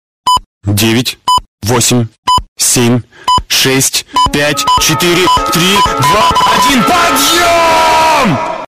Другие рингтоны по запросу: | Теги: будильник